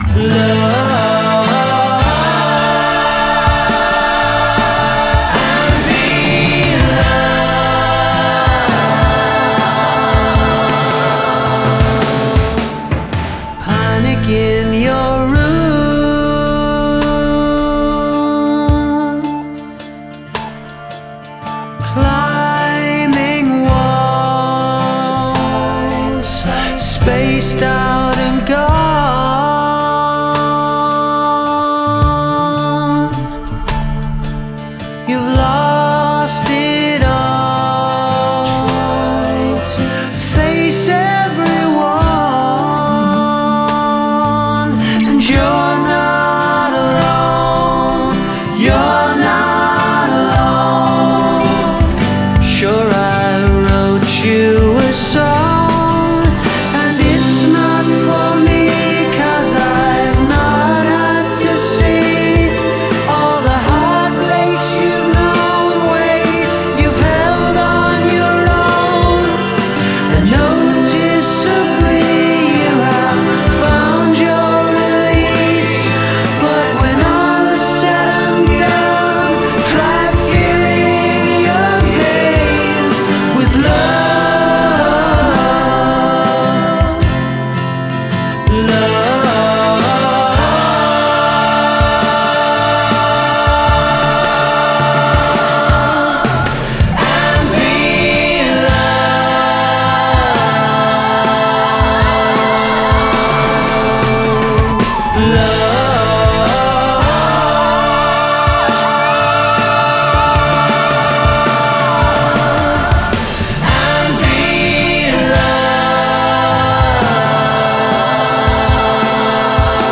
A slow and emotional departure from the Irish duo.